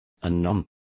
Complete with pronunciation of the translation of anon.